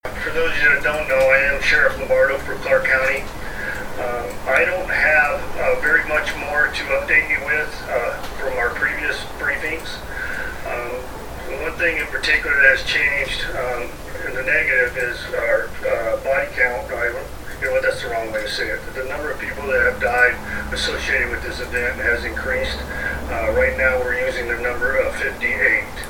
TV news coverage from mass shootings
shooting.mp3